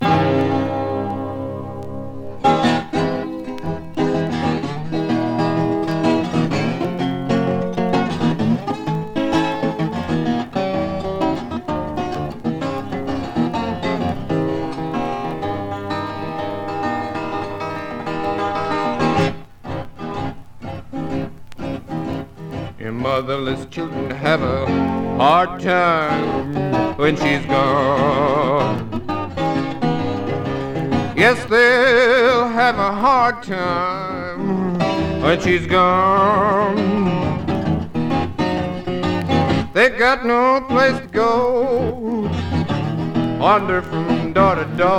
Blues, Folk, World　USA　12inchレコード　33rpm　Mono